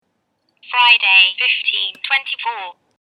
Slijepe osobe – Govorni ručni satovi DianaTalks govore 10 jezika, koriste prirodni ljudski glas.